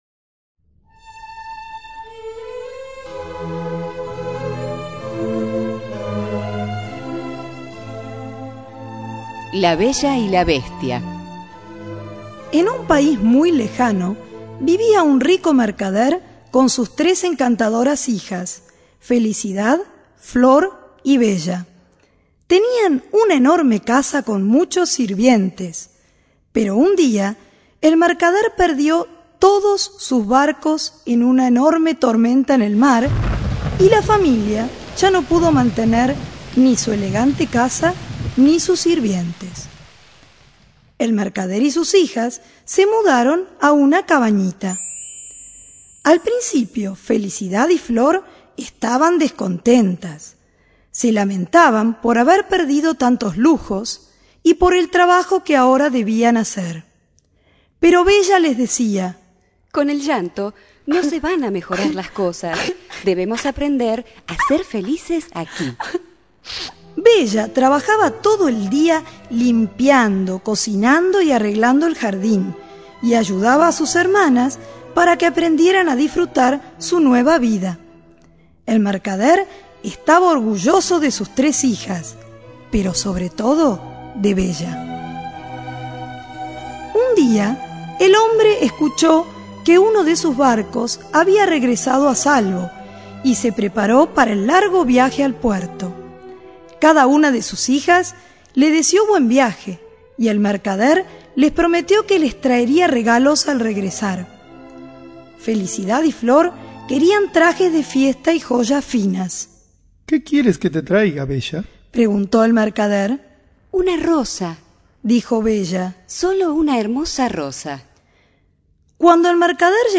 Audiolibros
*Audiolibros grabados por voluntarios de HSBC.